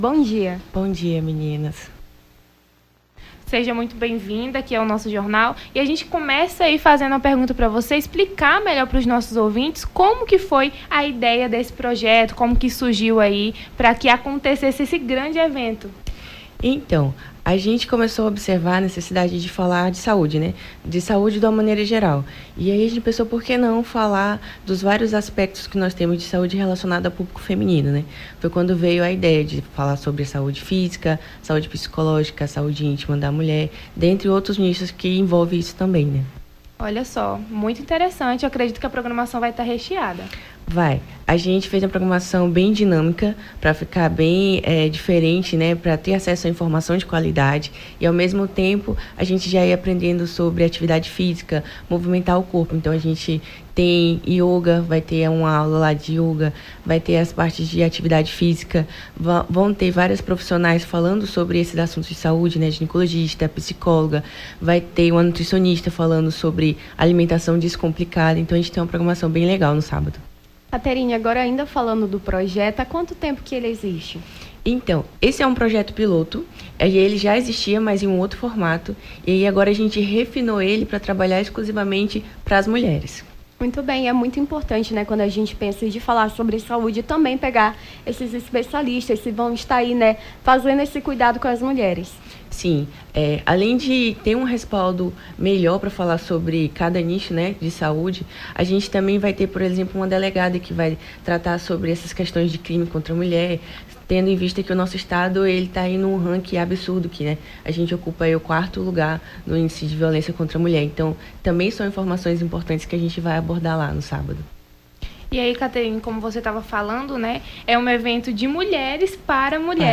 Nome do Artista - CENSURA - ENTREVISTA (PROJETO VIDA TODA) 14-12-23.mp3